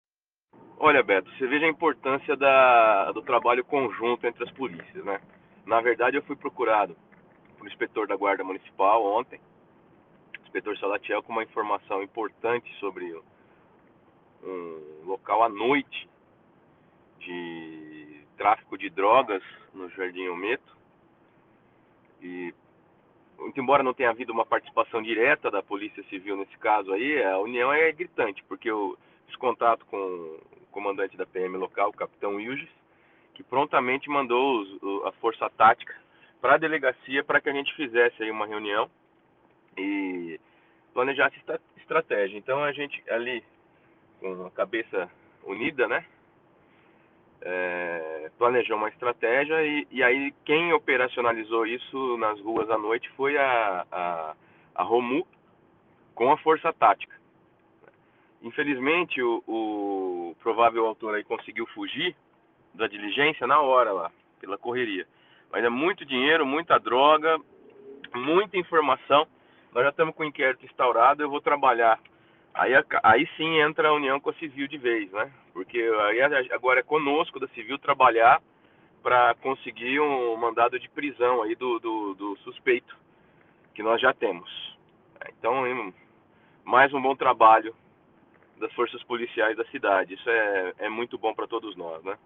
Durante entrevista para a nossa reportagem ele destacou mais esse ótimo trabalho feito pelas forças de segurança do município: